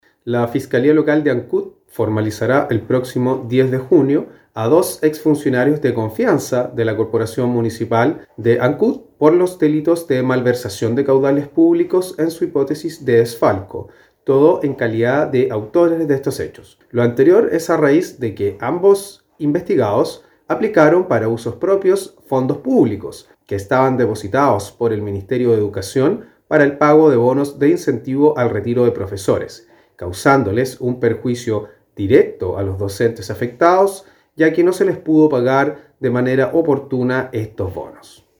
Escuchemos lo señalado por el fiscal Fernando Metzner al respecto de esta decisión de llevar a audiencia ante el Juzgado de Garantía de Ancud a estos dos ex directivos de la corporación municipal de Ancud.